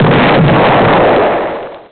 snd_badexplosion.mp3